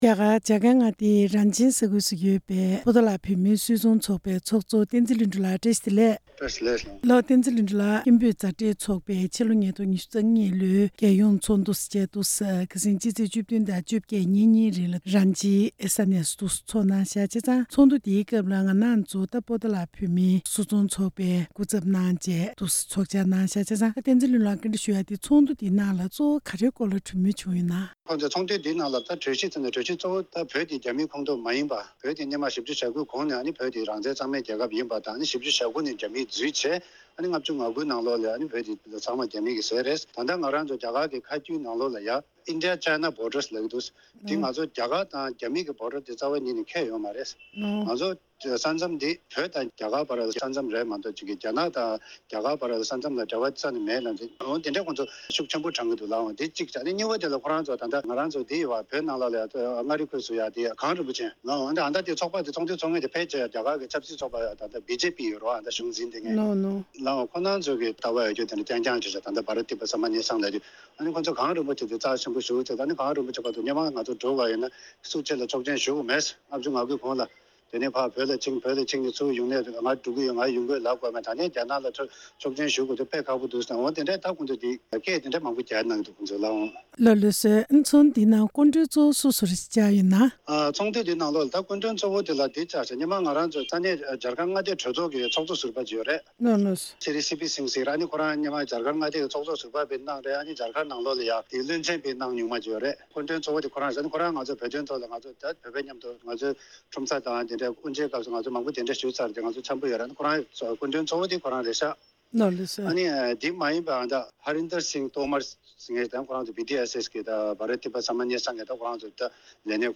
འབྲེལ་ཡོད་མི་སྣར་ཐད་ཀར་ཞལ་པར་བརྒྱུད་གནས་འདྲི་ཞུས་པ་གསན་རོགས་གནང་།